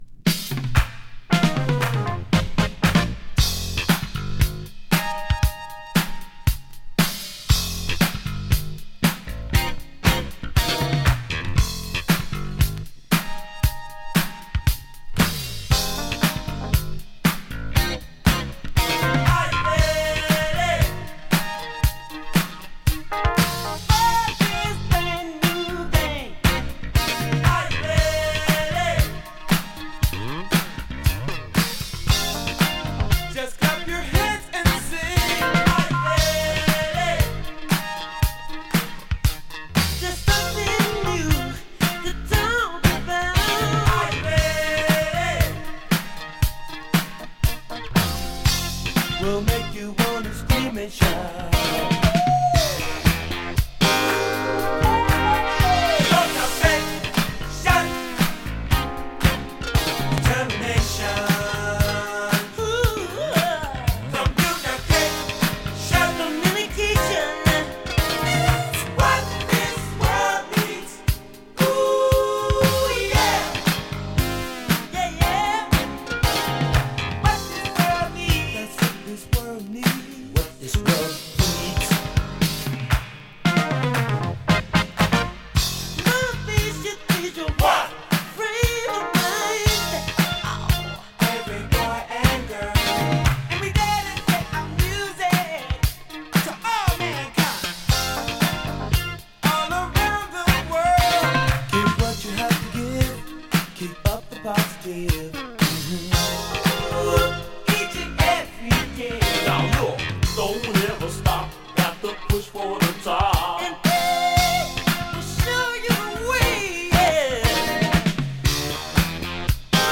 【FUNK】 【BOOGIE】